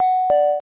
camera_shutter_dingdong.wav